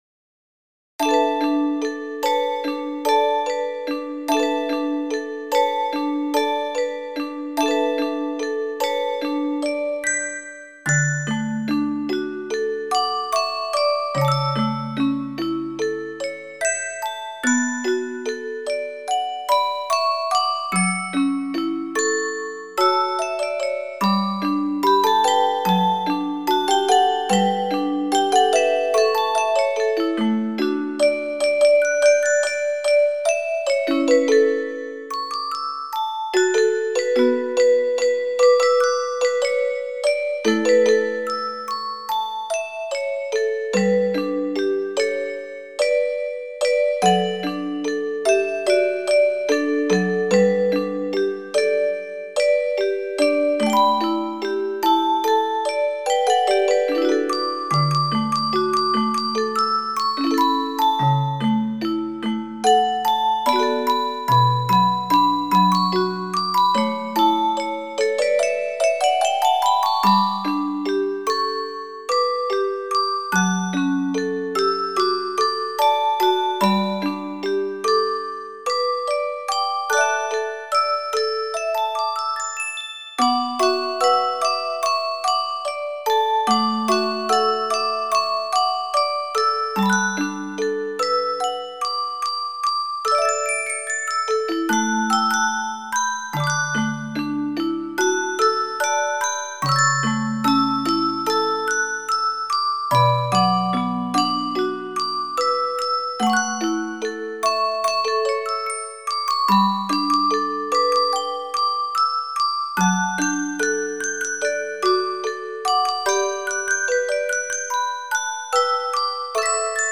ミュージックボックスMP3